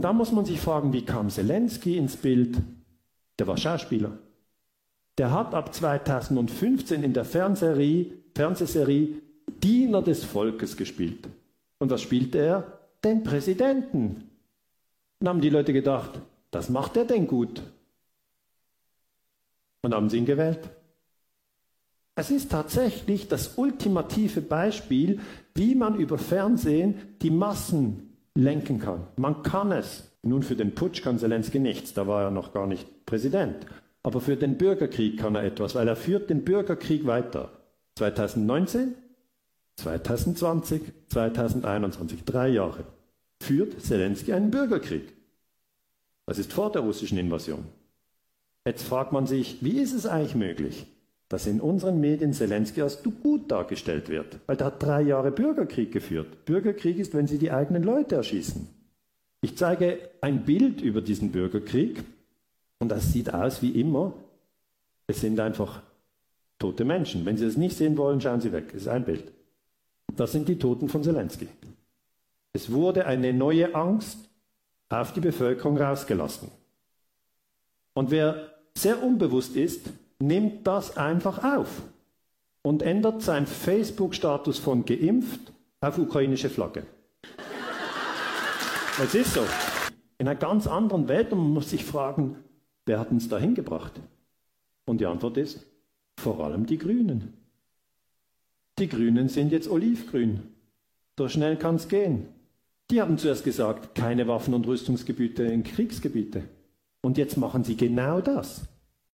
Der Schweizer Historiker und Friedenforscher Dr. Daniele Ganser erklärt in einem Vortrag, dass das in den westlichen Medien verbreitete Bild vom ukrainischen Präsidenten Selenskij nicht der Realität entspricht.